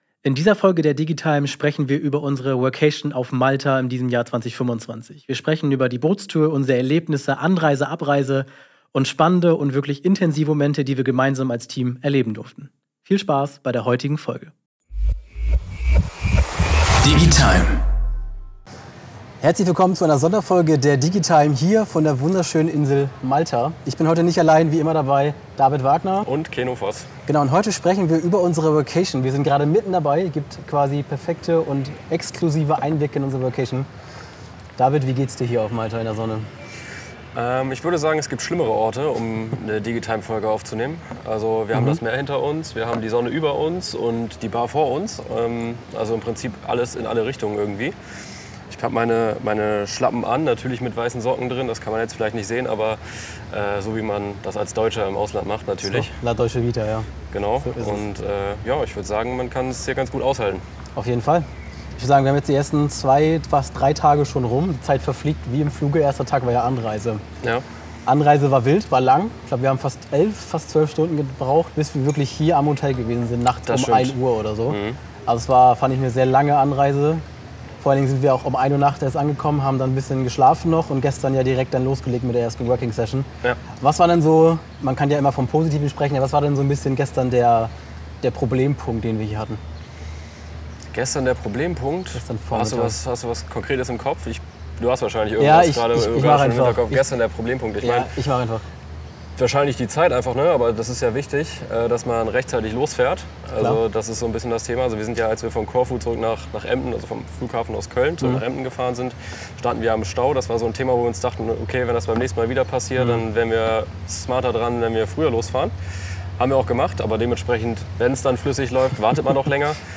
Direkt von der sonnigen Mittelmeerinsel berichten wir von unseren Erlebnissen: Von der Anreise über Teamabenteuer bis zur entspannten Bootstour. Wir sprechen über intensive Momente im Team, wie sich das Arbeiten unter Palmen anfühlt – und was eine Workation für die Zusammenarbeit wirklich bedeutet.